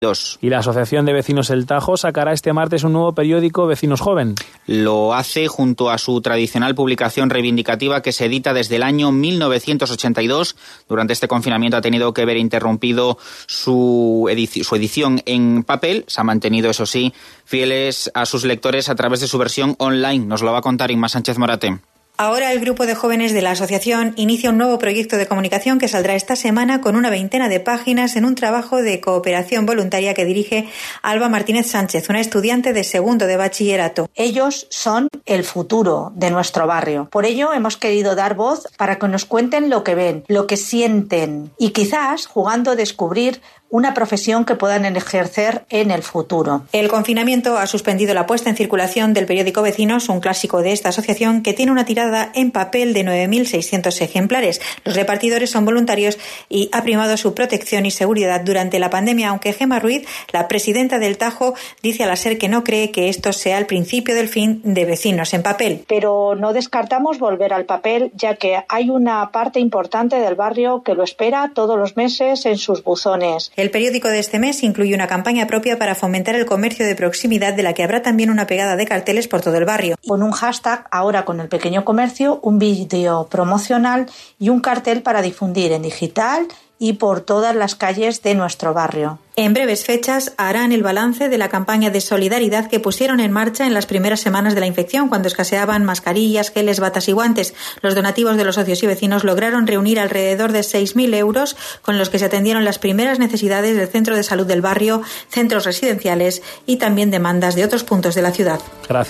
Corte de Audio de la entrevista realizada en el programa Hoy por Hoy Toledo en la SER
Entrevista-Lanzamiento-Vecinos-Joven.mp3